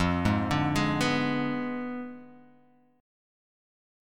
Fdim7 chord